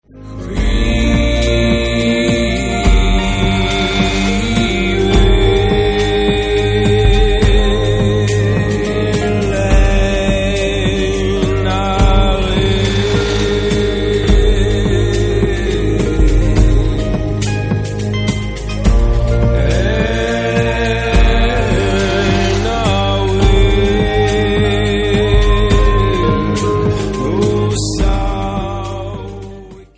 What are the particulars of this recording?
MP3 64kbps-Stereo